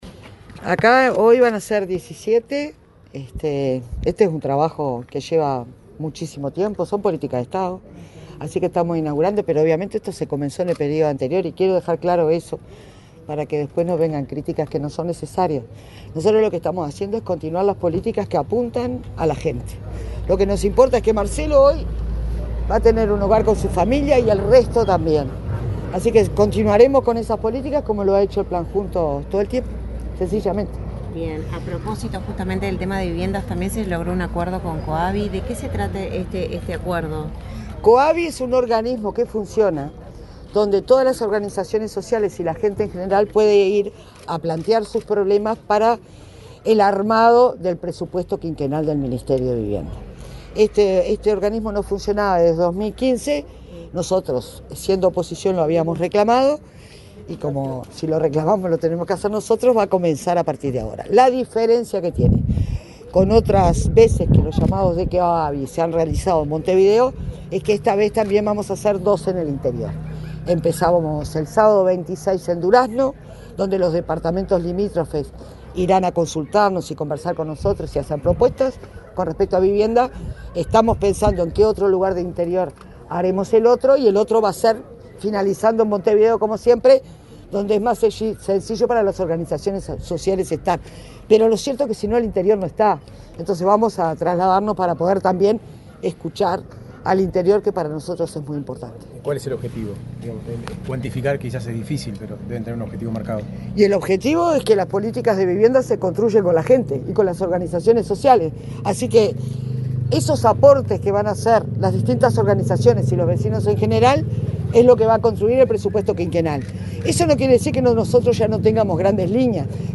Declaraciones de la ministra de Vivienda, Cecilia Cairo
La ministra de Vivienda, Cecilia Cairo, dialogó con la prensa, antes del acto de entrega de 17 viviendas en el barrio Punta de Rieles, en Montevideo.